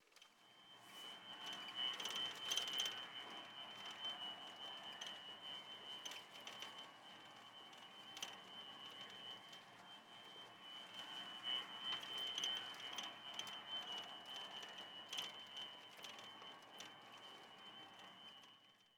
Ambiente interior de un avión Boeing 747
avión
Sonidos: Transportes